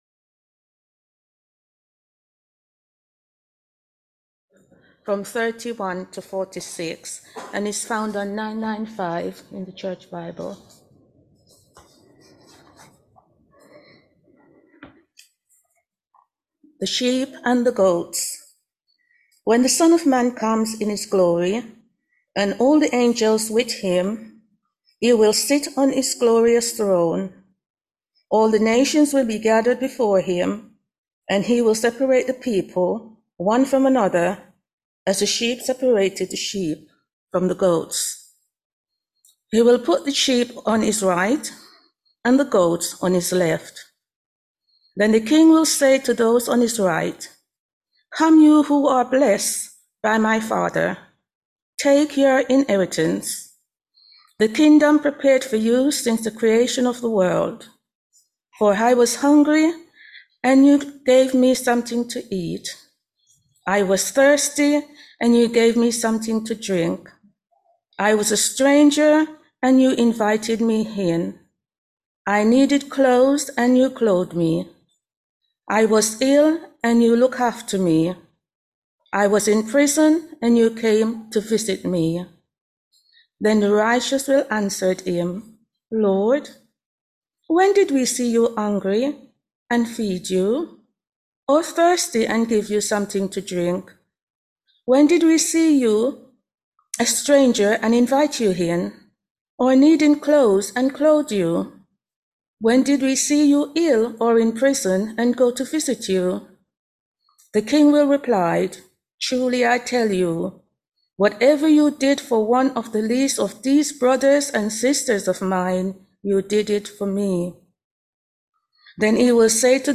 Matthew 25vs31-46 Service Type: Sunday Morning All Age Service Topics